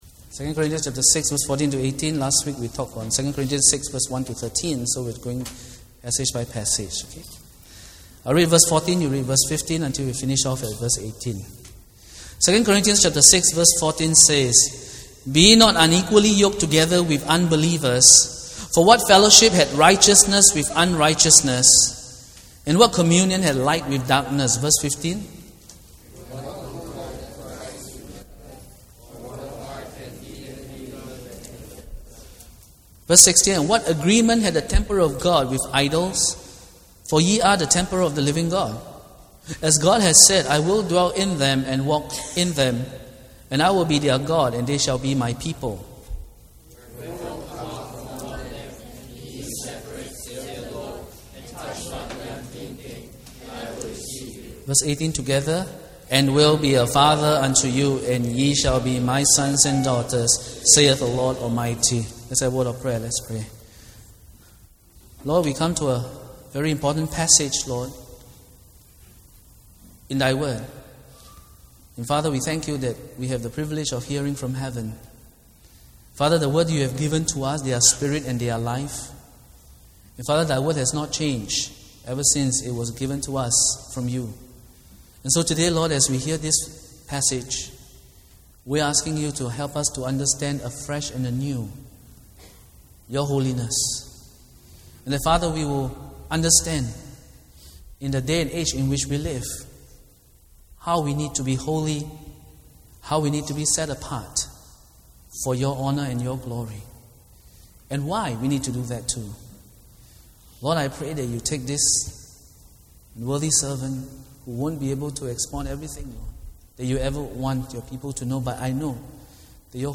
Download the sermon audio here Download the notes to this sermon here